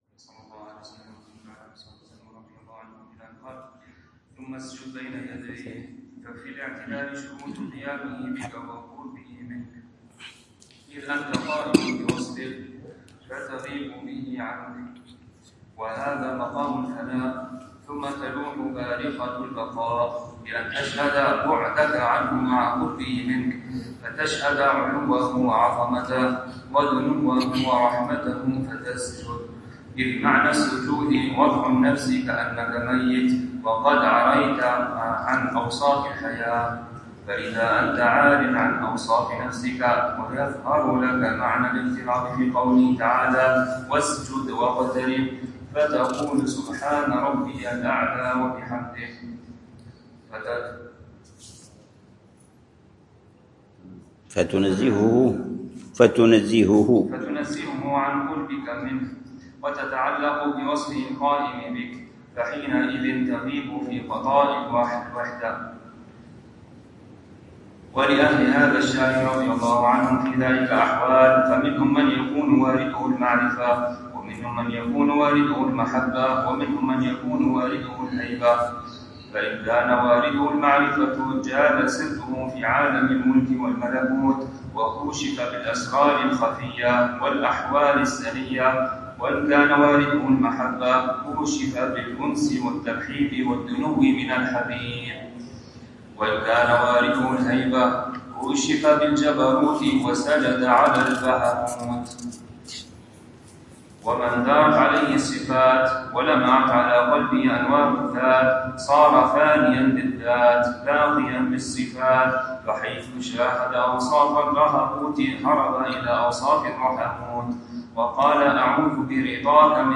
الدرس الرابع من شرح العلامة الحبيب عمر بن حفيظ لكتاب صفة صلاة المقربين للعلامة الحبيب الحسن بن صالح البحر الجفري رحمه الله، يوضح فيها صفة صلاة